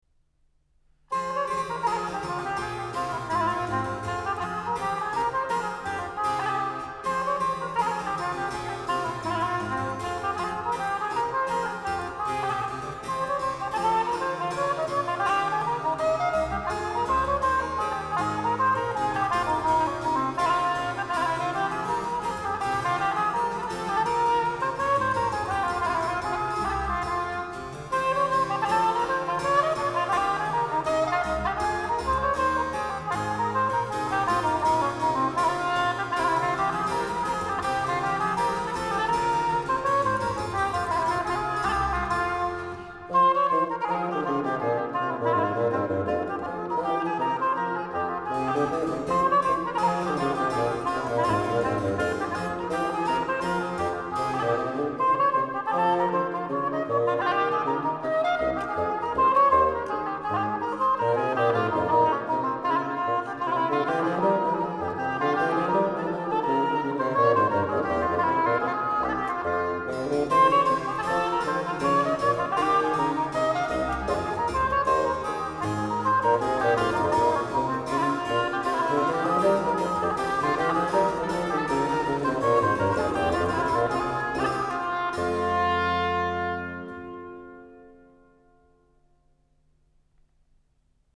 Savall et le concert des nations
Le tempo et le style gavotte (une danse) sont les plus reconnaissables dans le récent enregistrement de Jordi Savall. Légèreté et finesse du jeu contribuent à l'équilibre sonore marqué par les aigus cristallins du clavecin peu épais.